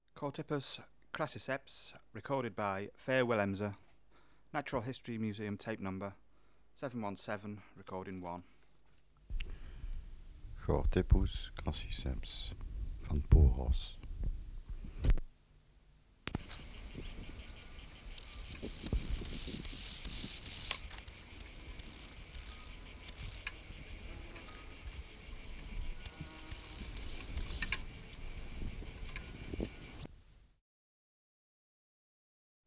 Species: Chorthippus (Glyptobothrus) crassiceps